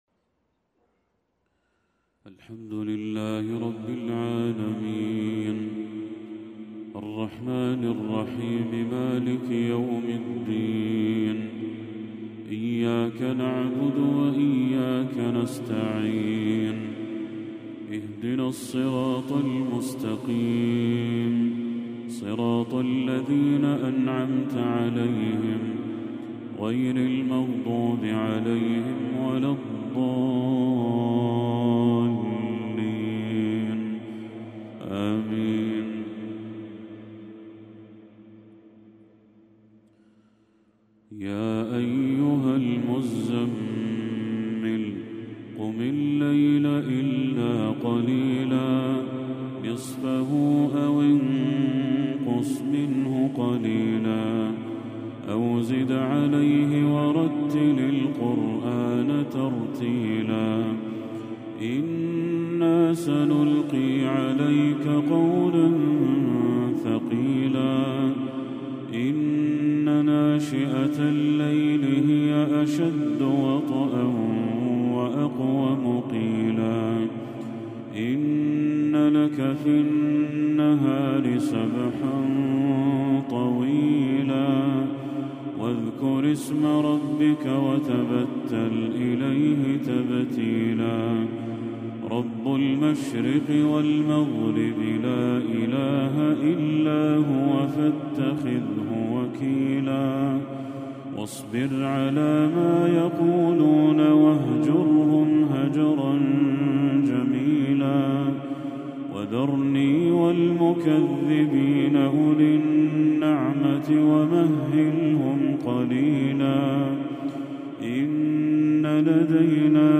تلاوة بديعة لسورة المزمل كاملة للشيخ بدر التركي | عشاء 26 ربيع الأول 1446هـ > 1446هـ > تلاوات الشيخ بدر التركي > المزيد - تلاوات الحرمين